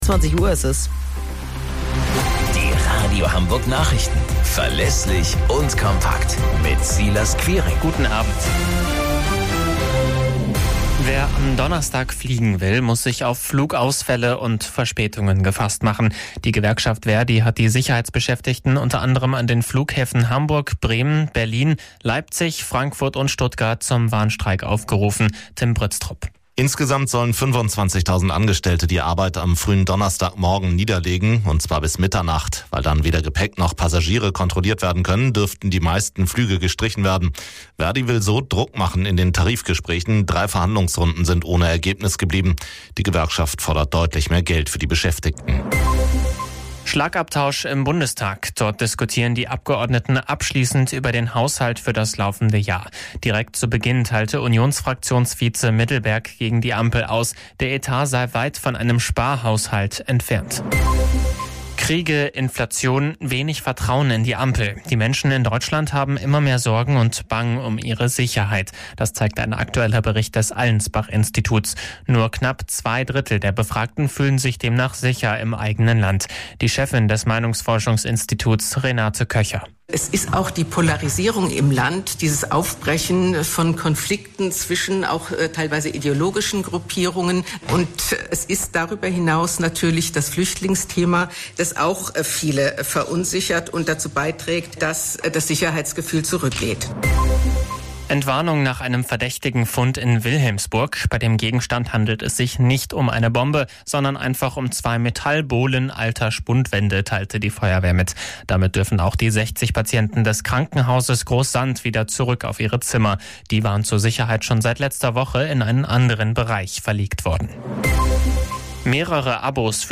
Radio Hamburg Nachrichten vom 07.04.2024 um 15 Uhr - 07.04.2024